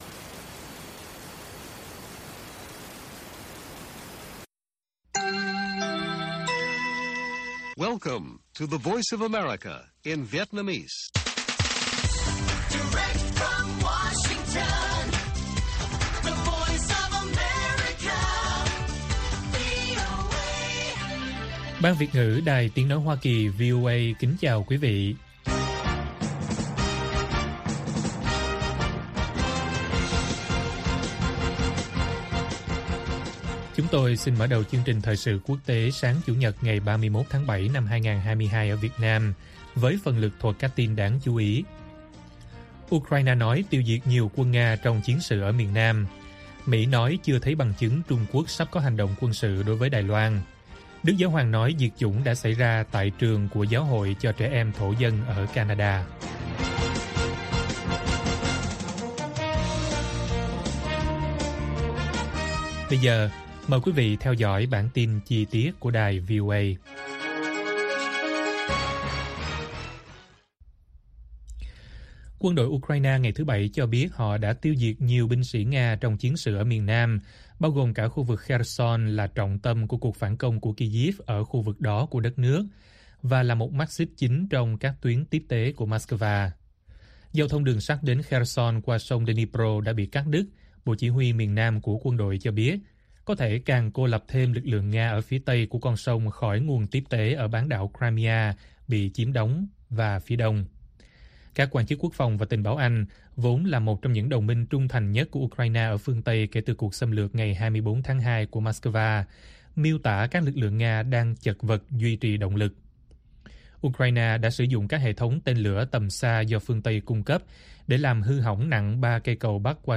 Ukraine nói tiêu diệt nhiều quân Nga trong chiến sự ở miền nam - Bản tin VOA